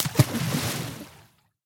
assets / minecraft / sounds / liquid / splash.ogg
splash.ogg